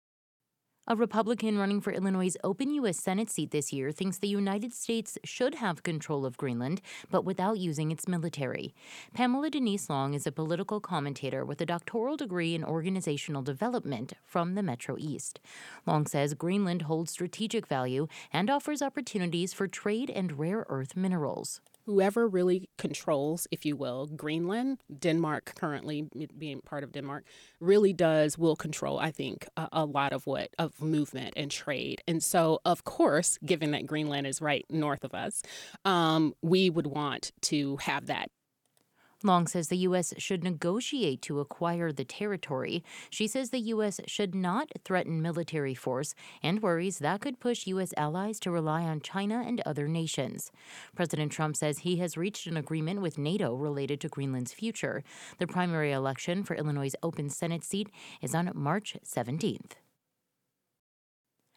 Interview Highlights